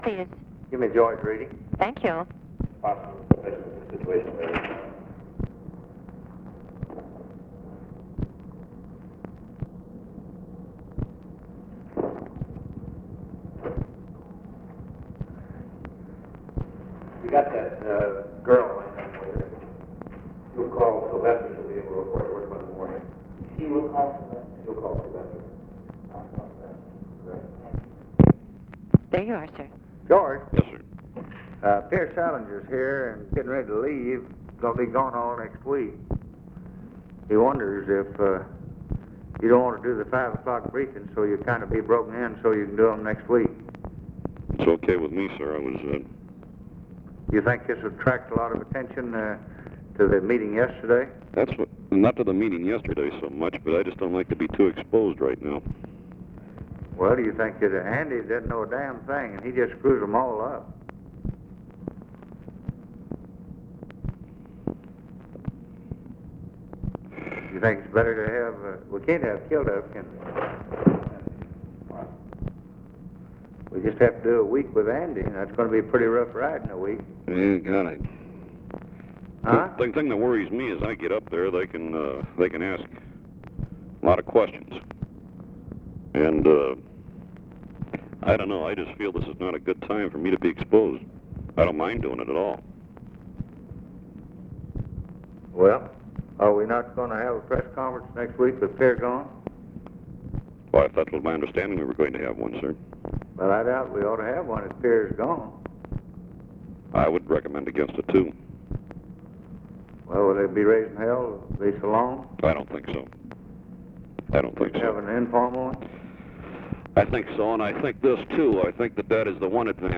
Conversation with GEORGE REEDY and OFFICE CONVERSATION, January 24, 1964
Secret White House Tapes